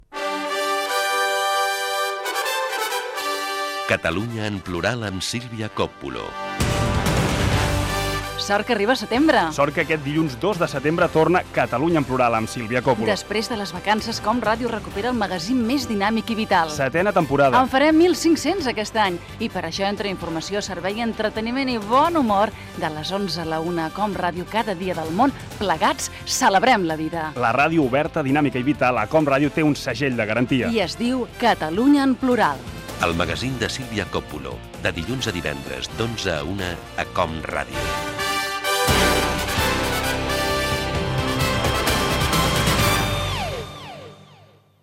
05e9654dfbb308abe51649eeb4a6719bbbc4a072.mp3 Títol COM Ràdio Emissora COM Ràdio Barcelona Cadena COM Ràdio Titularitat Pública nacional Nom programa Catalunya en plural Descripció Promoció de la temporada 2002/2003.